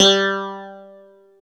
13 CLAV G3-R.wav